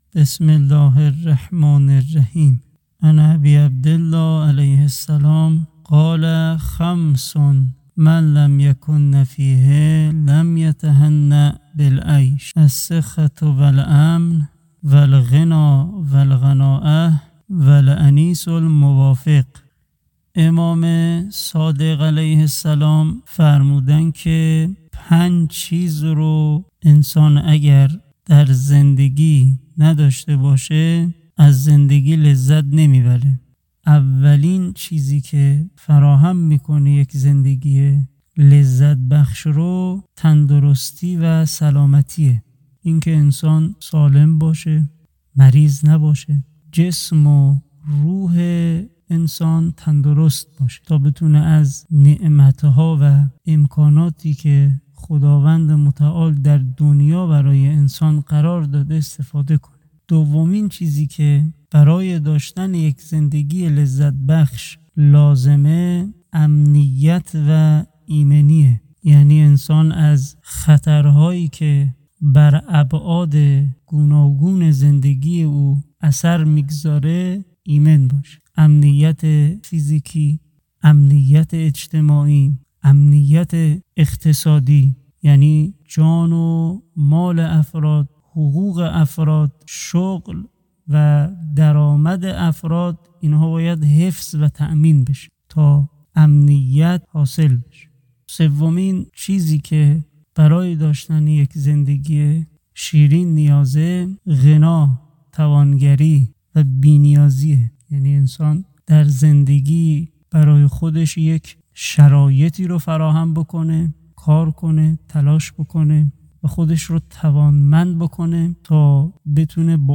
حیات دل، نام مجموعه روایت‌‌خوانی با موضوعات گوناگونی است